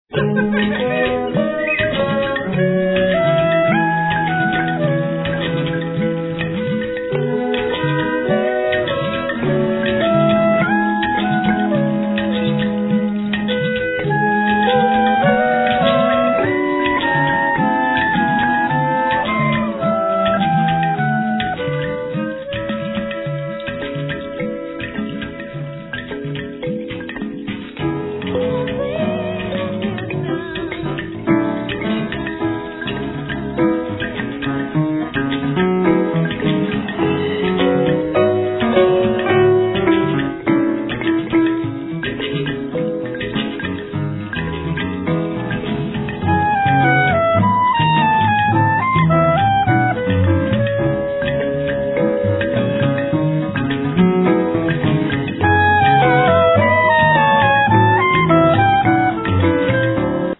percussion
piano
winds
cello, piano
ethnic winds
composition, piano, voice, acordion, berimbao